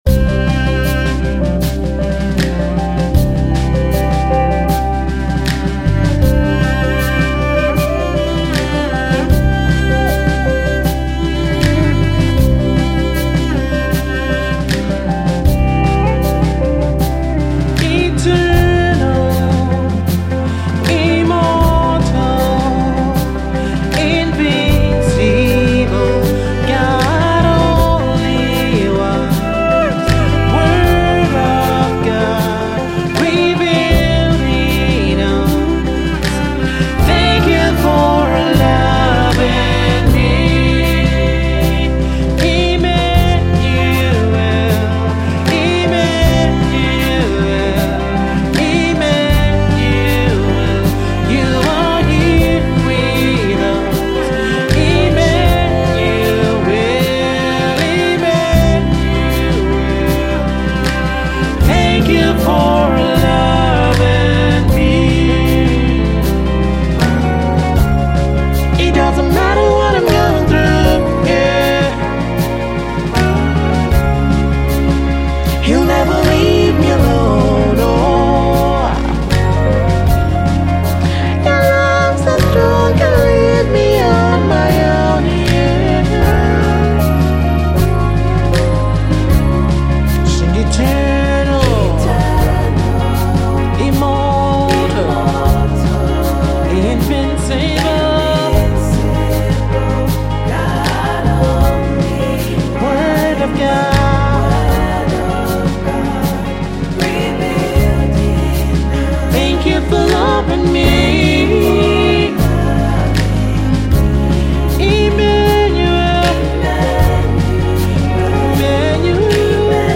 a spirit-filled motivational song